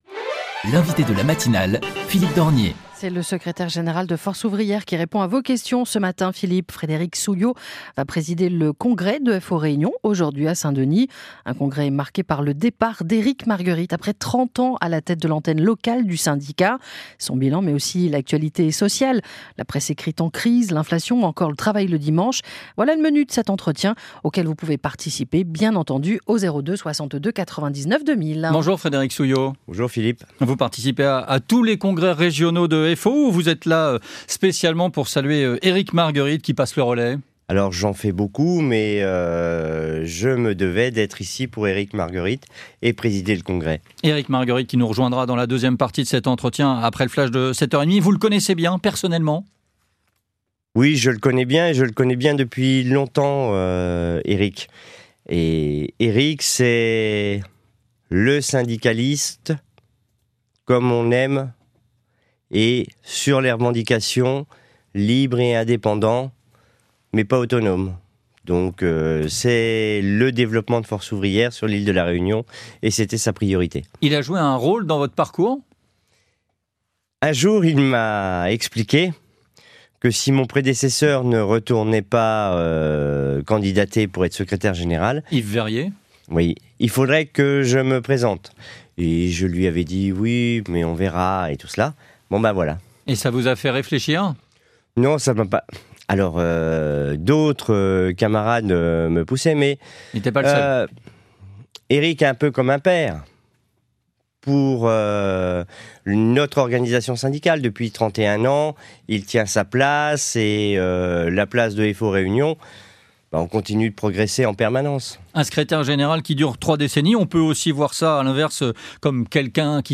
l_invite_de_la_matinale_a_re_ecouter_sur_reunion_la_1ere.mp3